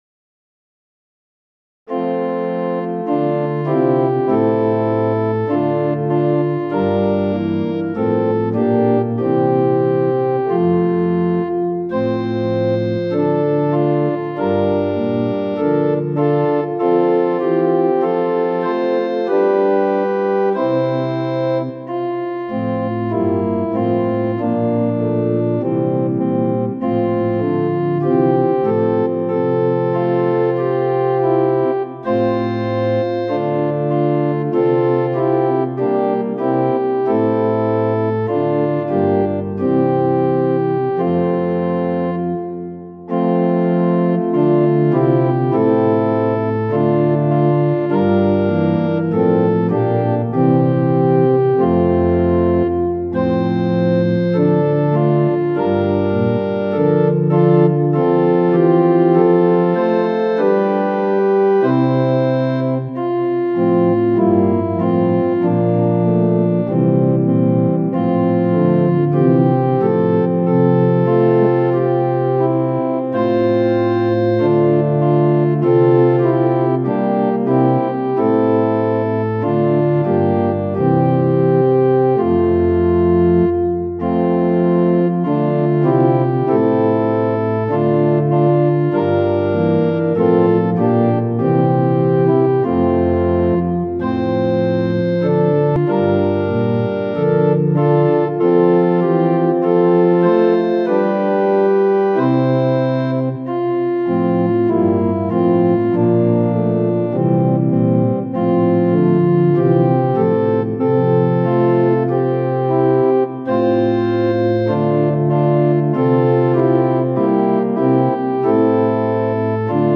♪賛美用オルガン伴奏音源：
・柔らかい音色(ロア・フルート8')部分は前奏です
・はっきりした音色(プリンシパル8'+4')になったら歌い始めます
・節により音色が変わる場合があります
・間奏は含まれていません
Tonality = F
Pitch = 440
Temperament = Equal